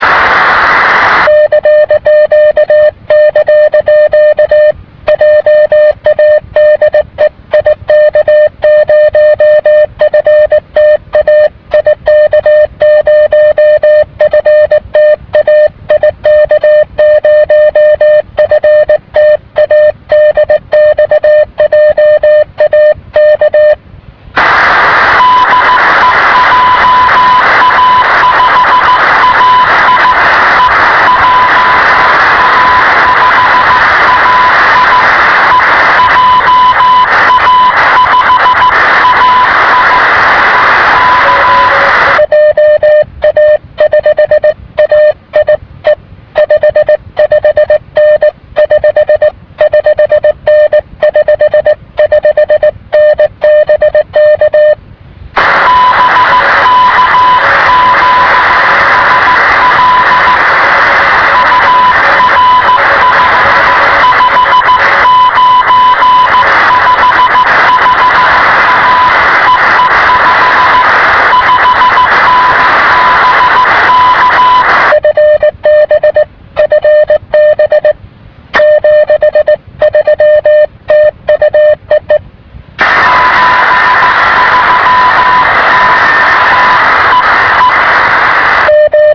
Es Multi Hop A6, A4, JA (I0 area)